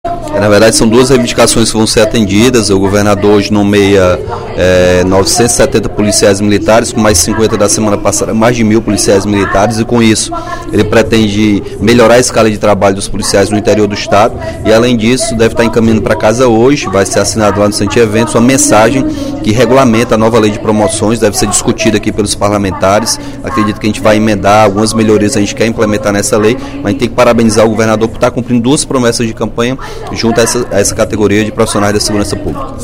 Durante o primeiro expediente da sessão plenária desta terça-feira (14/04), o deputado Capitão Wagner (PR) enalteceu a postura do governador Camilo Santana, que vem contemplando os policiais militares, cumprindo com promessas de campanha.